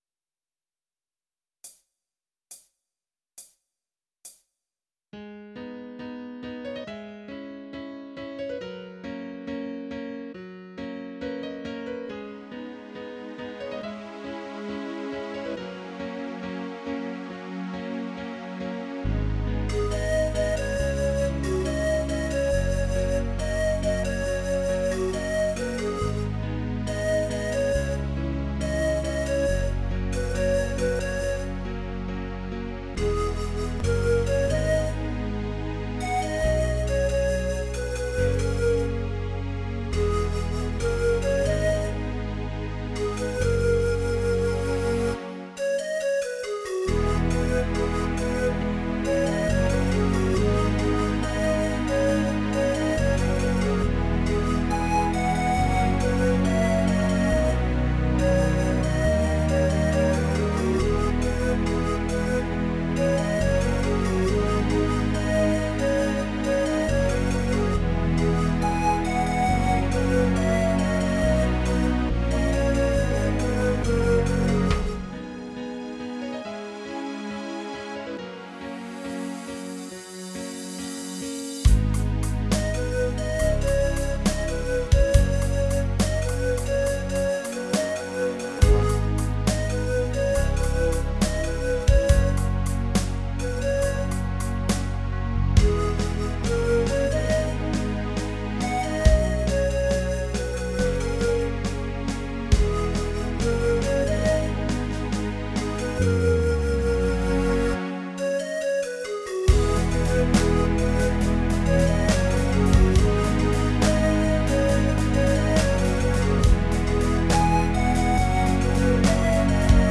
MIDI Karaoke version
PRO MIDI INSTRUMENTAL VERSION